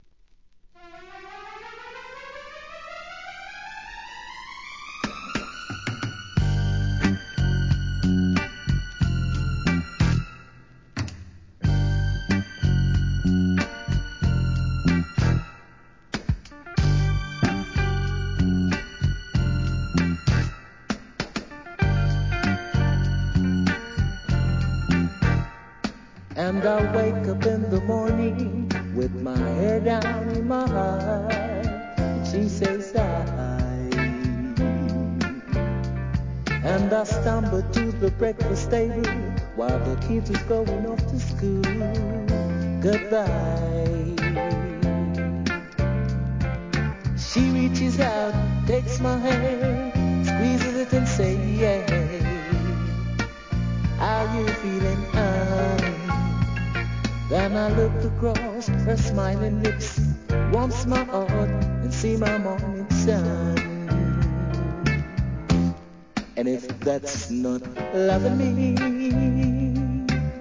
REGGAE
SWEETでMOODIEな極上カヴァー曲揃ってます♪